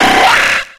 Cri d'Insécateur dans Pokémon X et Y.